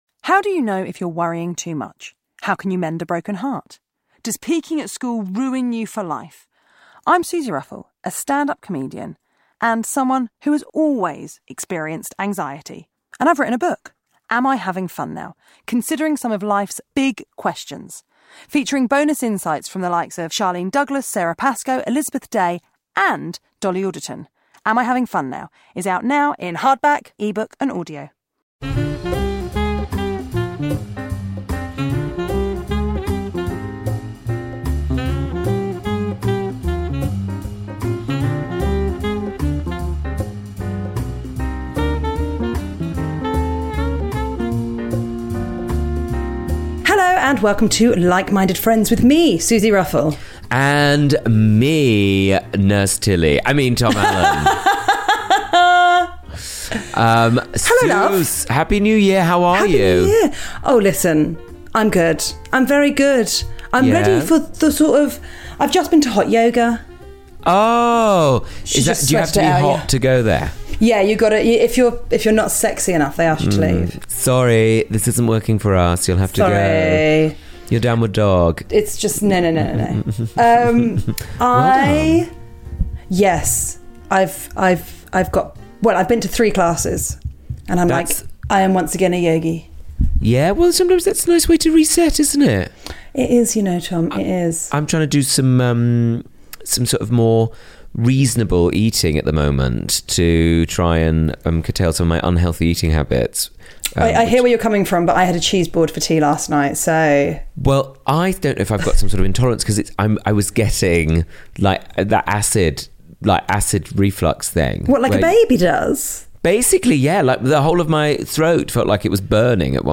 Comedians and dearest pals Tom Allen and Suzi Ruffell chat friendship, love, life and culture....sometimes...